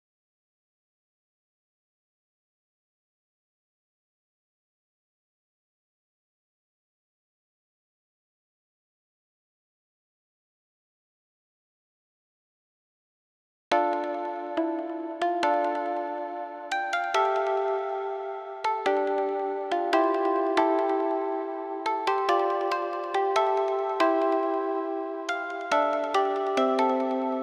BLACKHEART_keys.wav